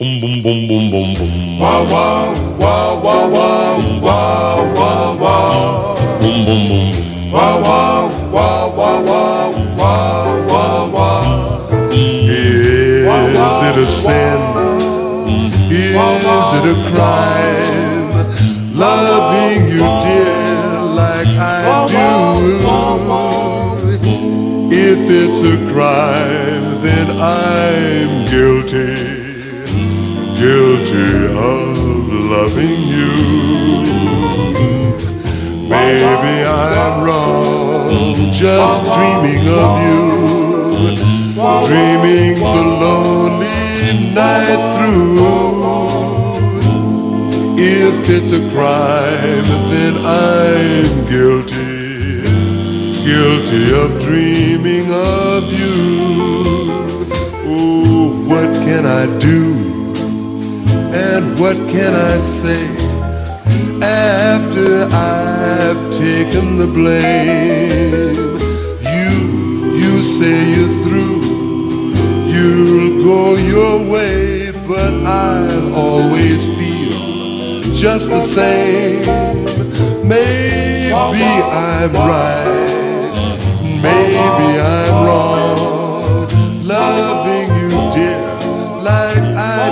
SOUL / SOUL / 60'S (US)
ヒップな68年ソウル・コンピ！
ノリよくヒップなナンバー多し！